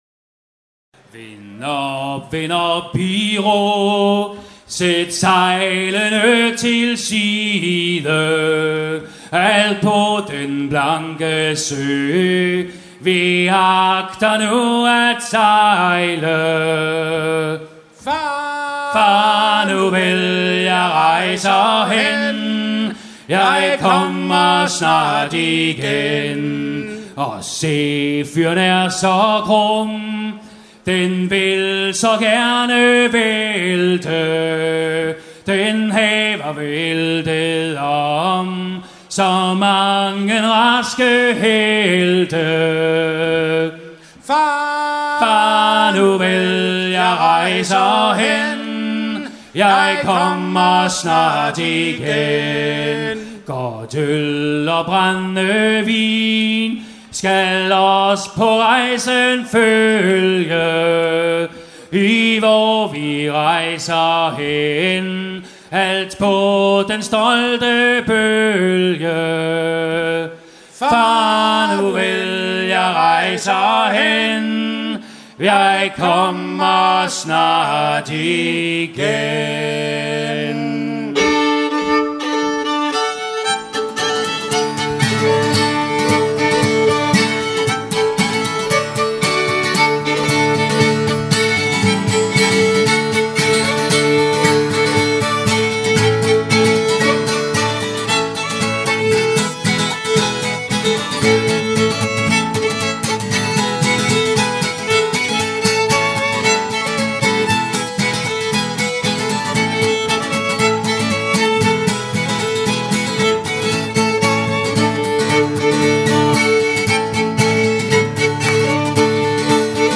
danse : polka ; danse : hopsa (Danemark)
Pièce musicale éditée